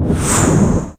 Woosh.wav